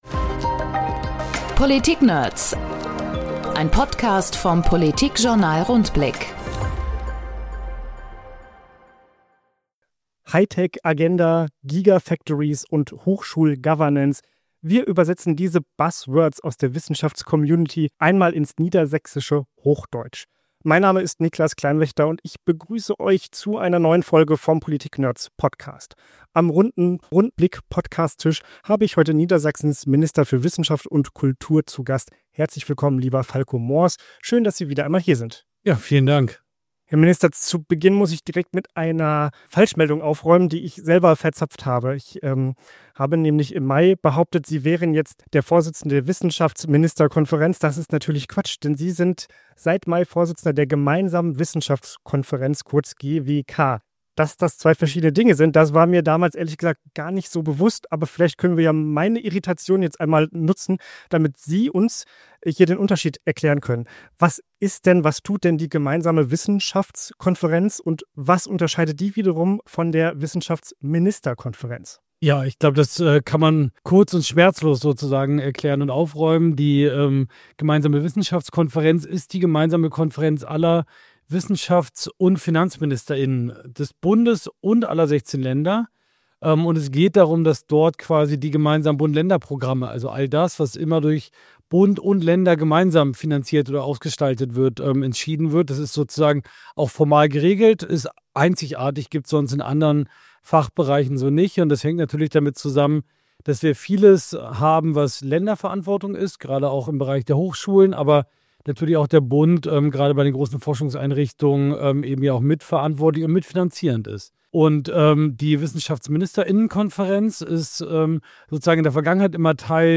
Niedersachsens Wissenschaftsminister im Rundblick-Gespräch Seit Mai ist Niedersachsens Wissenschaftsminister Falko Mohrs (SPD) Vorsitzender der Gemeinsamen Wissenschaftskonferenz von Bund und Ländern. In dieser Funktion treibt er gemeinsam mit Bundesforschungsministerin Dorothee Bär (CSU) die „Hightech-Agenda“ der schwarz-roten Bundesregierung voran.